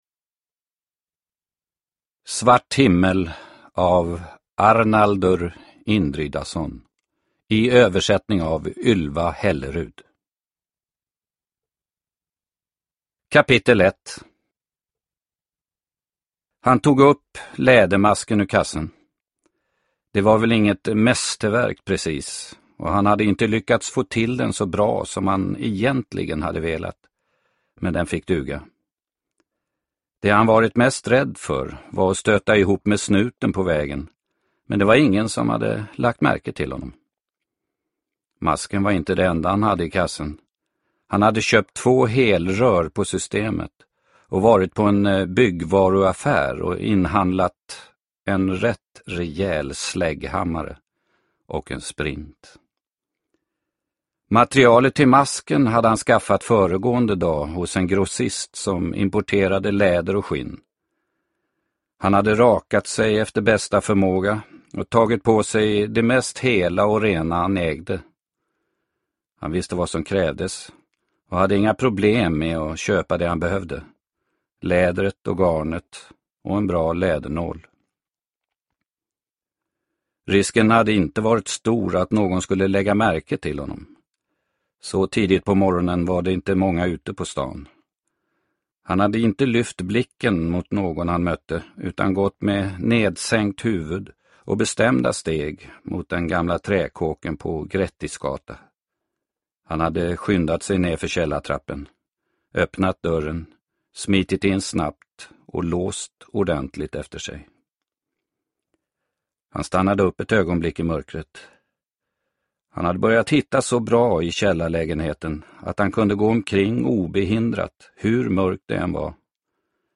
Svart himmel – Ljudbok – Laddas ner